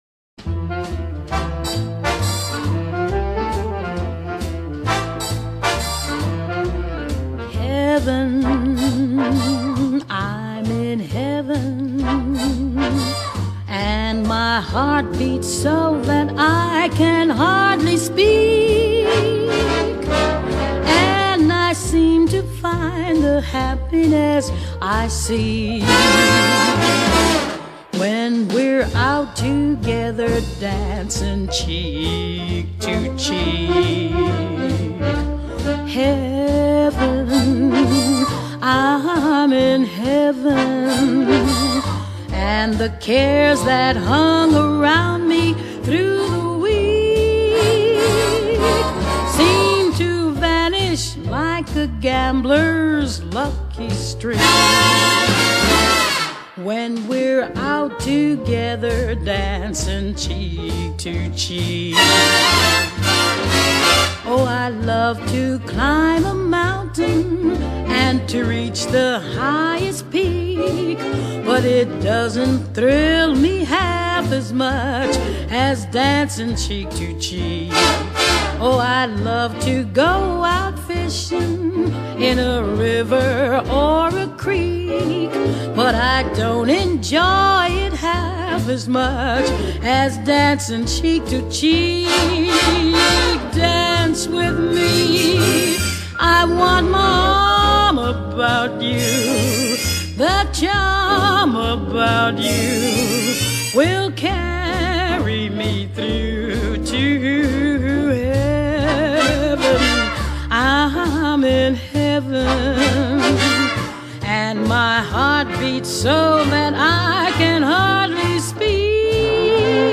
Genre: Jazz, Easy Listening, Lounge, Cover